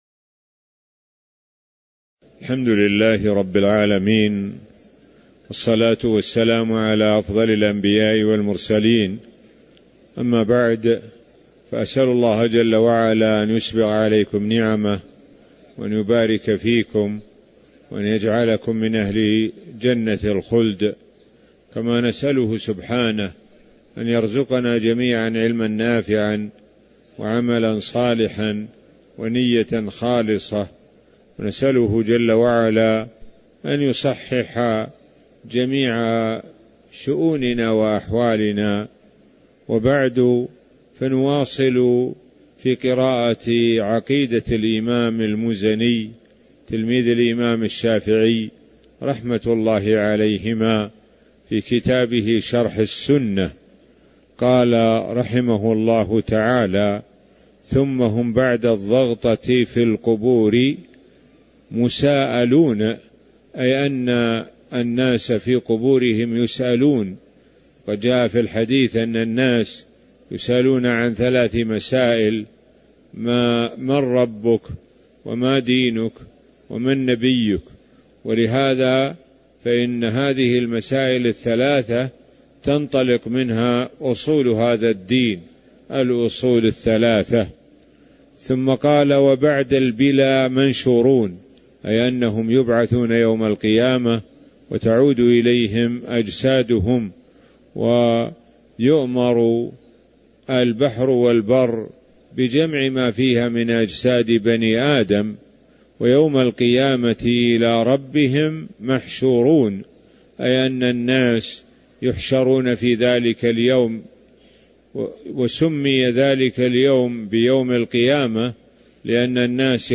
تاريخ النشر ١٩ ذو القعدة ١٤٤٣ هـ المكان: المسجد النبوي الشيخ: معالي الشيخ د. سعد بن ناصر الشثري معالي الشيخ د. سعد بن ناصر الشثري قوله: ثم هم بعد الضغطة في القبور مساءلون (06)